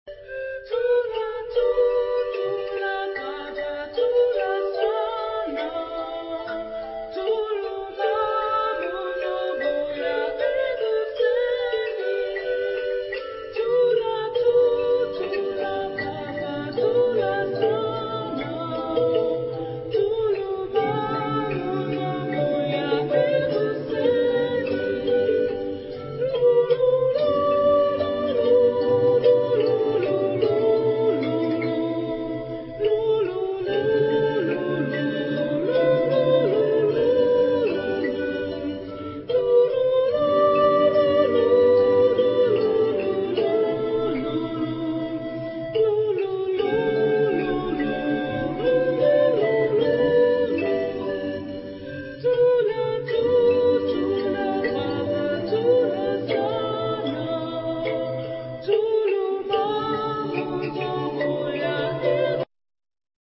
Genre-Style-Forme : Berceuse ; Traditionnel
Caractère de la pièce : affectueux ; beau ; doux ; calme
Type de choeur : SATB
Tonalité : sol majeur